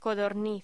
Locución: Codorniz